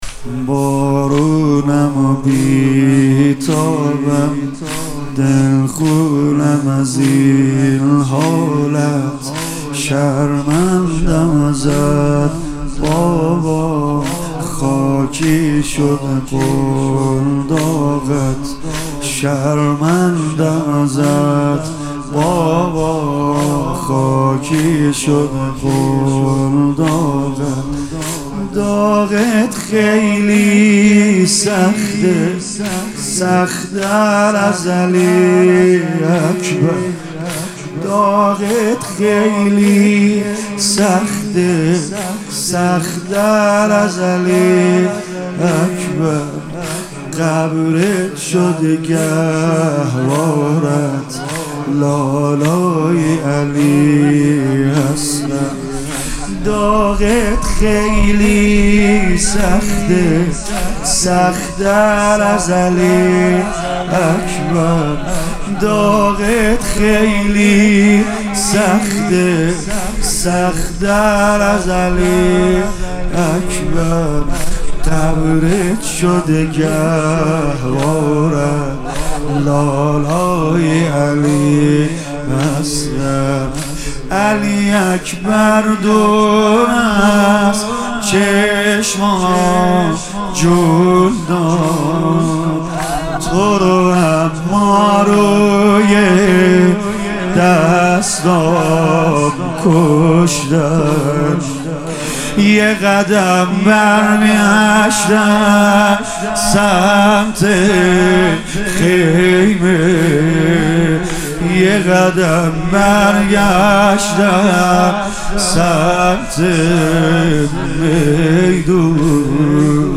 مداحی و مرثیه سرایی مداحان اهل بیت در شب هفتم محرم 1401 + دانلود
مداحی شب هفتم محرم با نوای سید رضا نریمانی